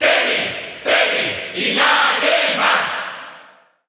Crowd cheers (SSBU) You cannot overwrite this file.
Terry_Cheer_Spanish_SSBU.ogg